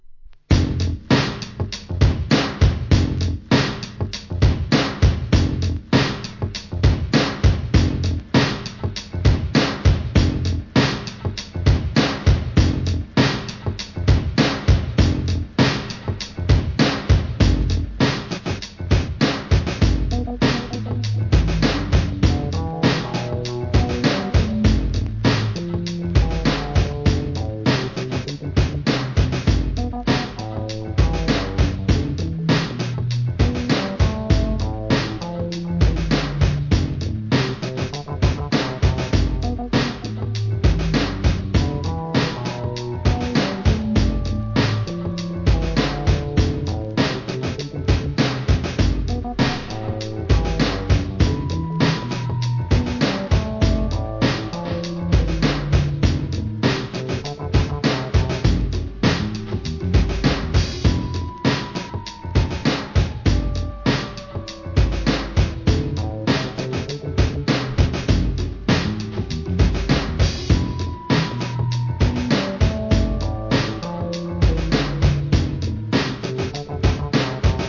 Ambientブレイクビーツ